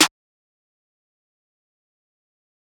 Snares
Metro Snare 4.wav